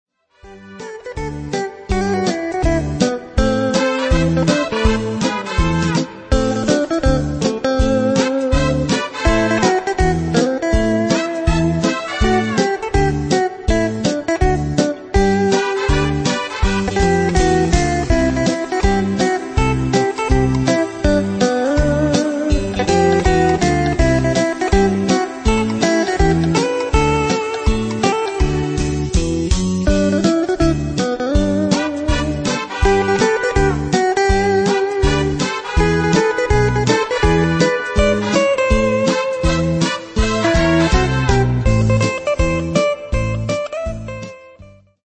fox-trot